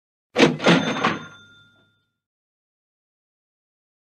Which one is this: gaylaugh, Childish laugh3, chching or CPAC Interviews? chching